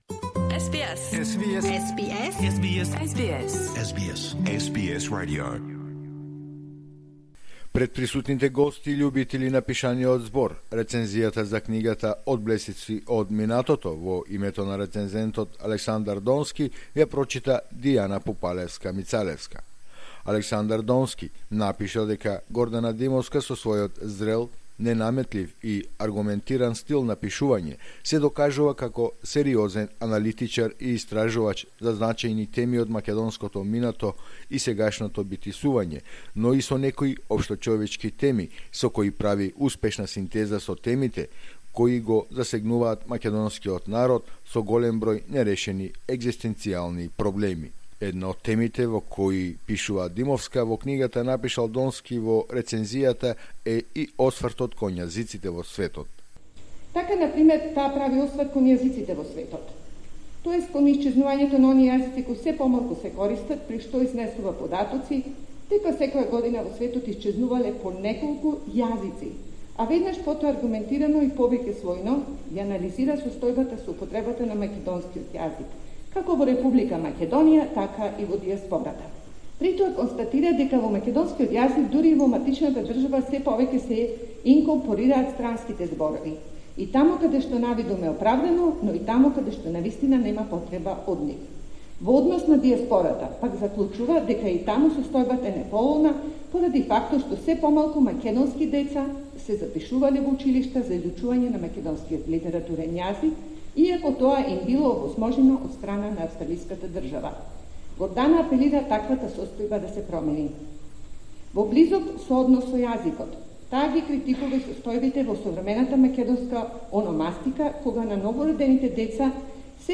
Промоција на книгата " Одблесоци од минатото"